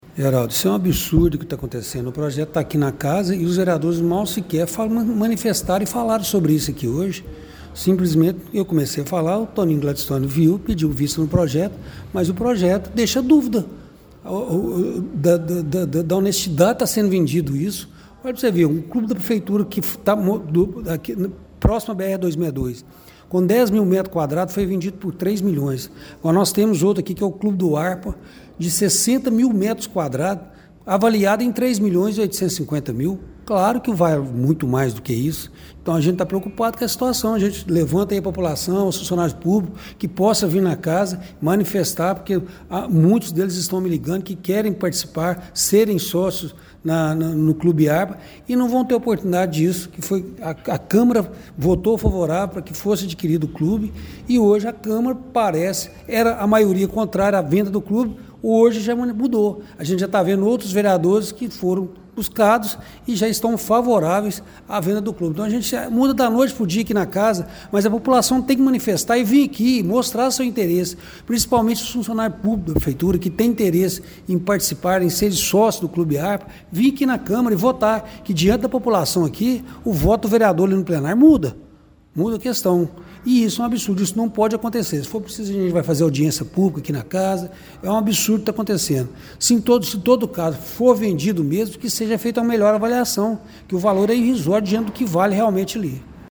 Durante a sessão realizada nesta terça-feira (07), o Projeto de Lei Ordinária nº 15/2026, que autoriza a prefeitura a vender o imóvel do extinto Clube Arpa, teve sua tramitação interrompida após um pedido de vista, revelando profundas divergências entre os parlamentares sobre o destino da área.